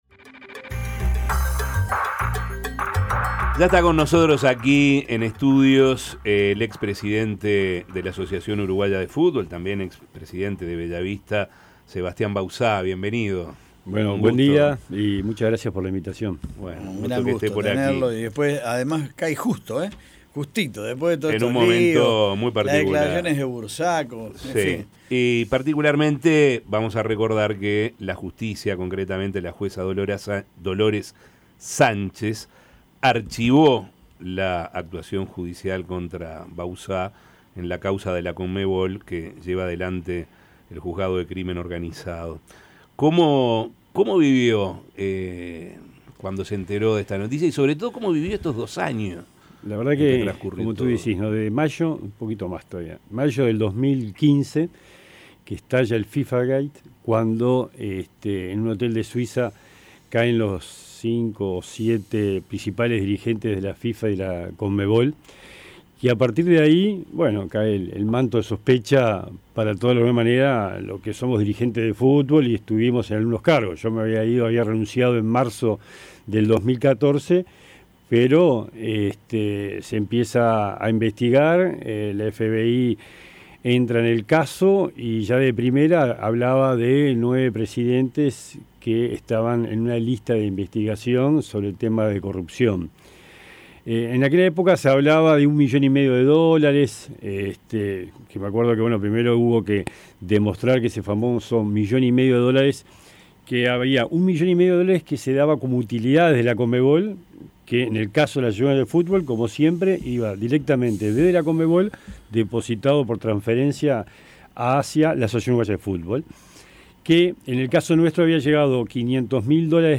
El expresidente de la Asociación Uruguaya de Fútbol (AUF) Sebastián Bauzá relató a La Mañana de El Espectador la peripecia que vivió en estos dos años que estuvo investigado por presunta corrupción cuando estuvo al frente de la AUF.
Escuche la entrevista de La Mañana: